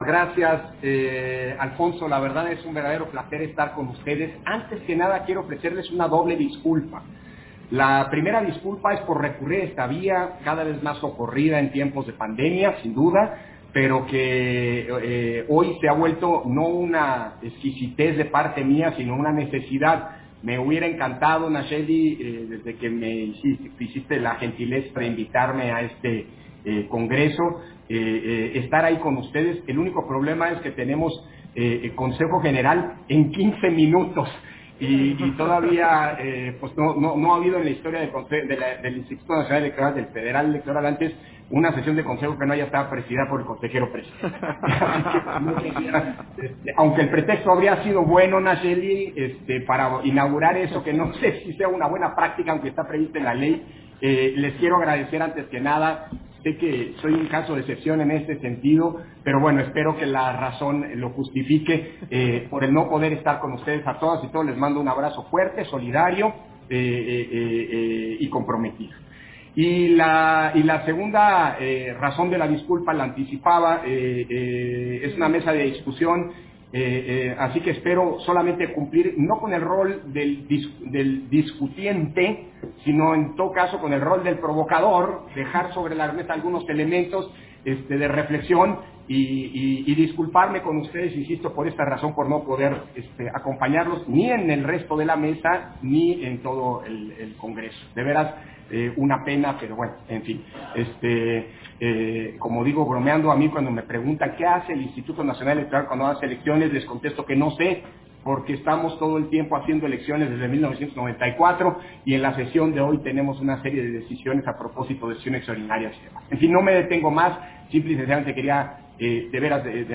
Intervención de Lorenzo Córdova, en el LIV Congreso Nacional: El sistema jurisdiccional de protección de derechos humanos del siglo XXI